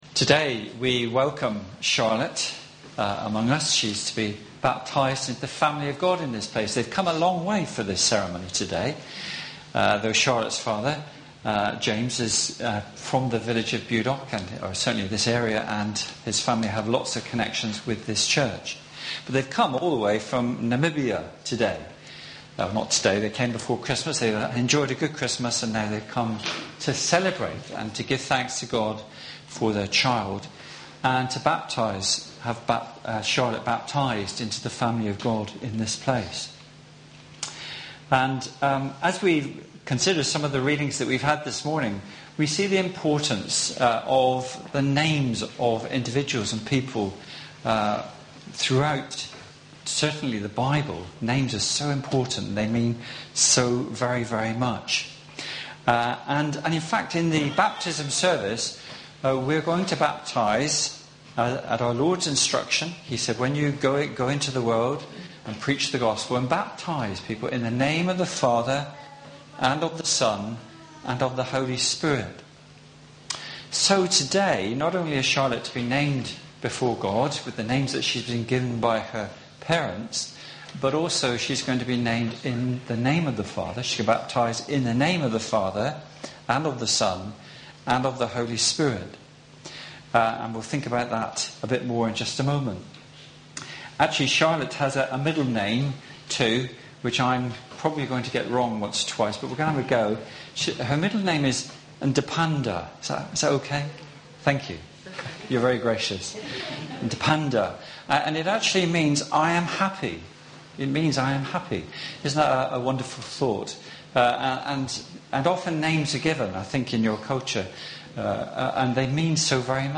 Sermon-1-jan-17.mp3